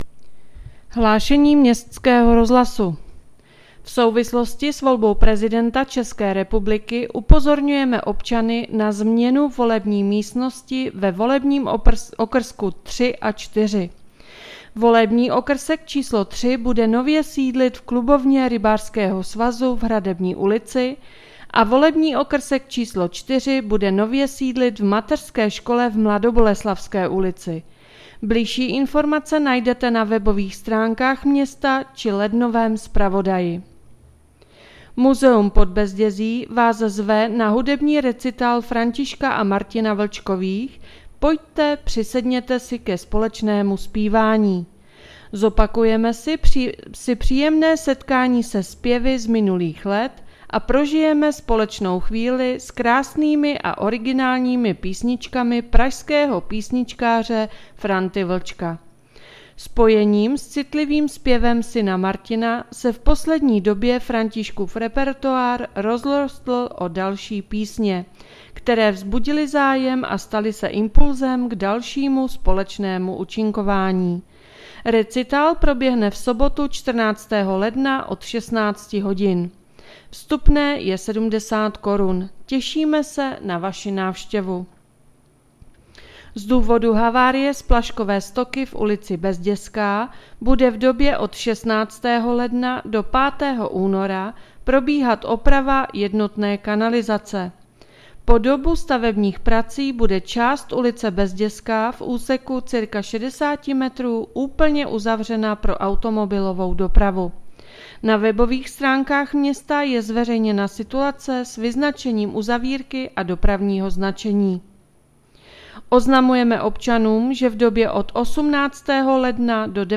Hlášení městského rozhlasu 13.1.2023